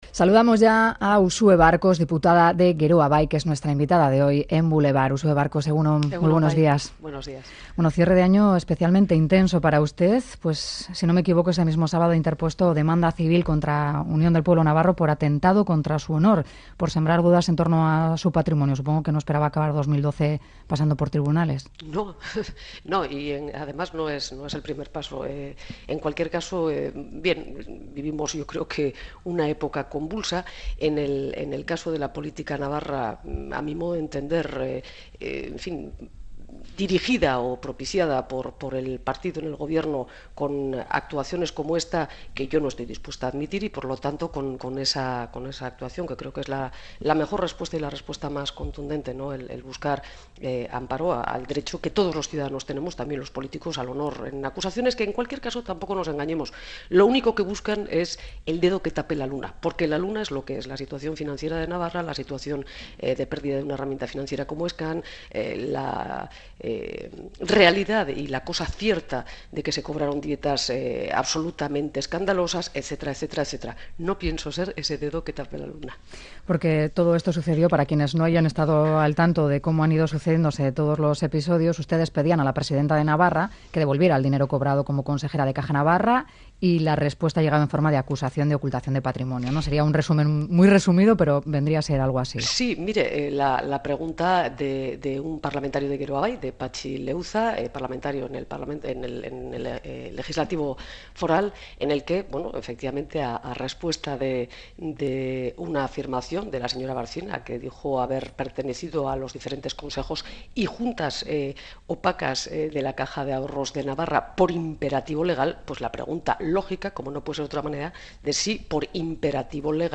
Audio de la entrevista a Uxue Barkos | Geroa Bai | Actualidad política